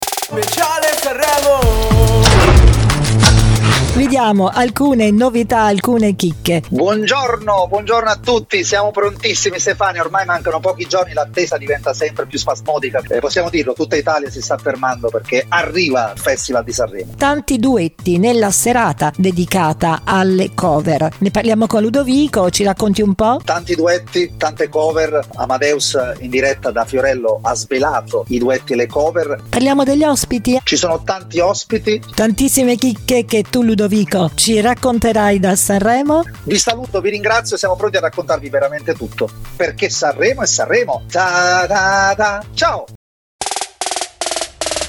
Il format prevede aggiornamenti e interviste agli artisti partecipanti ed è suddiviso in due appuntamenti giornalieri da Martedì 11 a Sabato 15 Febbraio e un appuntamento Domenica 16 Febbraio 2025.